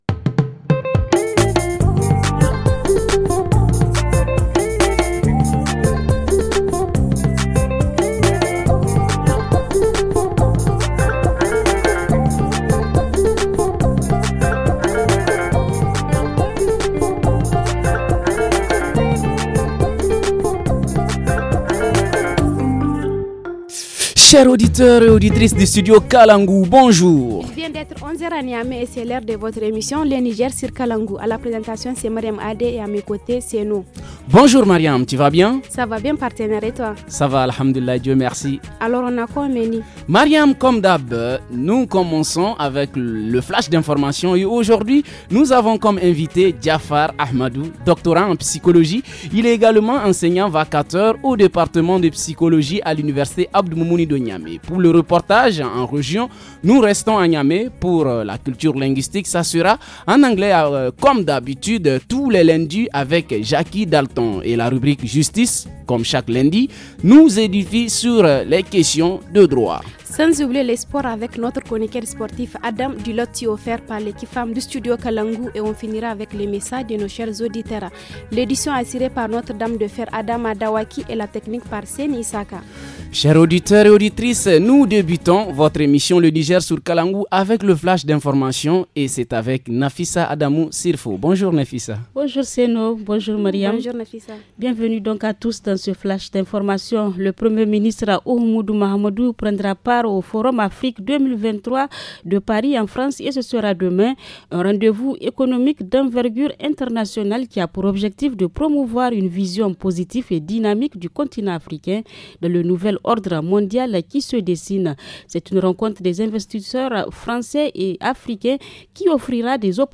-Reportage à Niamey sur l’impact de la loi sur le quota ;